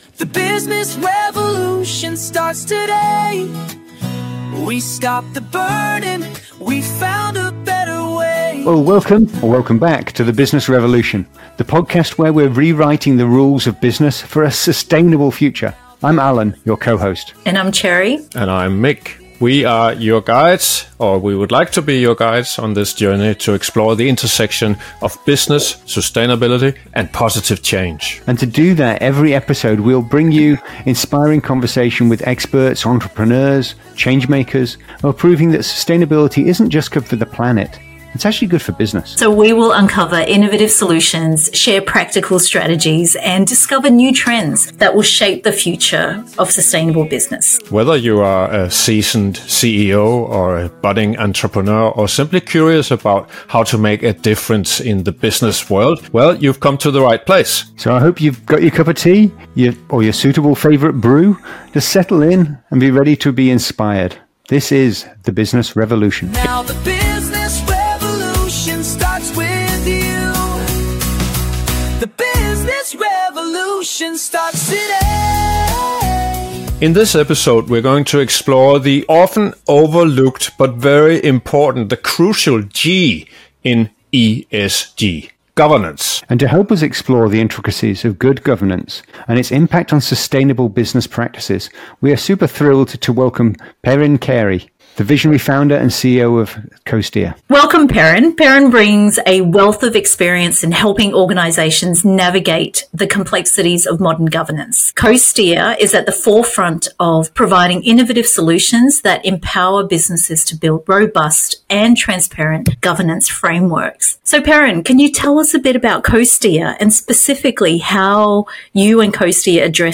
Join us for a thought-provoking conversation that will inspire you to see governance as a dynamic opportunity, a competitive edge and a path to building institutions worth inheriting.